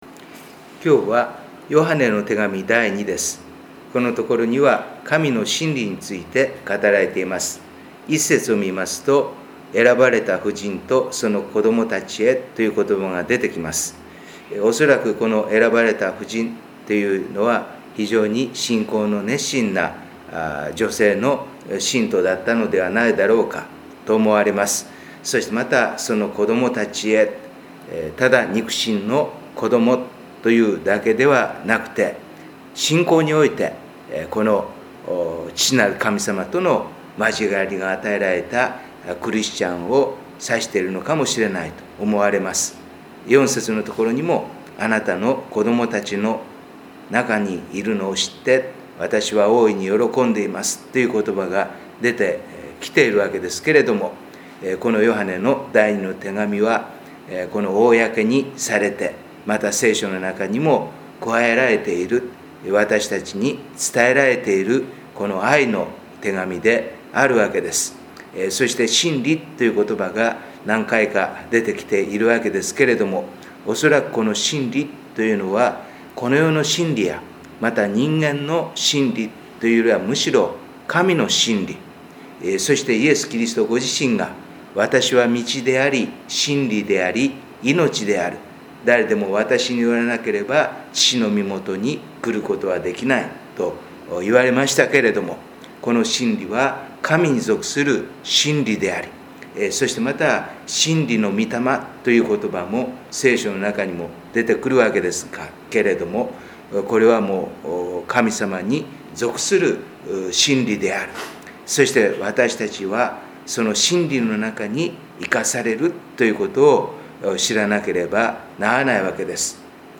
4月のデボーションメッセージ